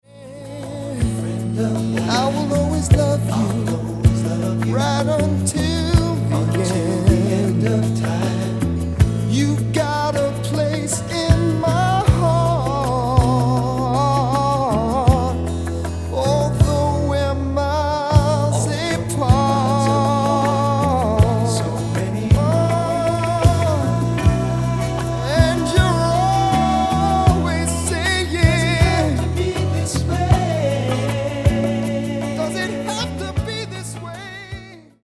Genere:   Disco Soul